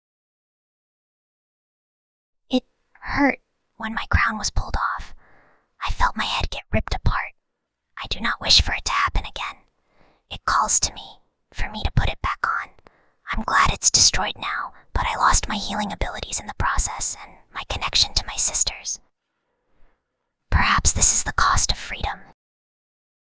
File:Whispering Girl 7.mp3
Whispering_Girl_7.mp3